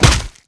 SFX monster_hit_skeleton.wav